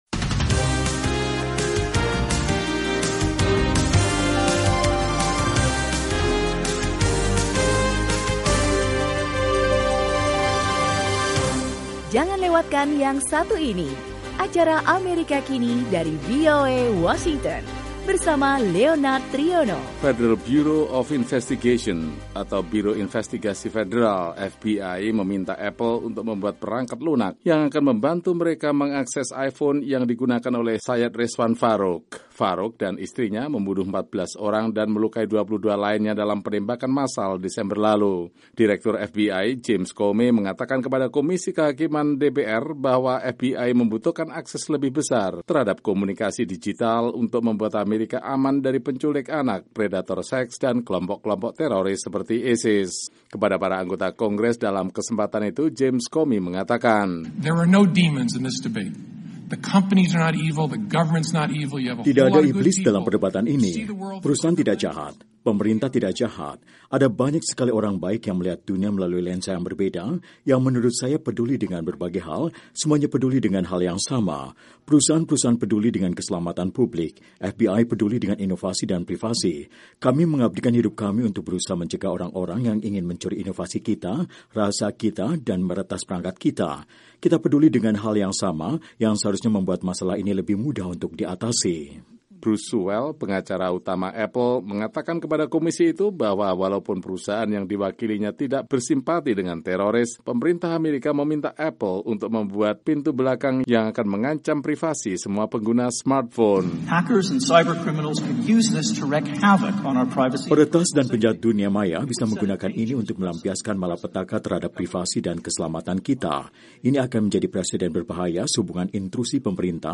Departemen Kehakiman Amerika dan raksasa teknologi Apple bersengketa terkait upaya memaksa Apple untuk membantu FBI mengakses sebuah IPhone yang digunakan oleh seorang teroris di San Bernardino, California, Desember lalu. Ikuti laporan koresponden VOA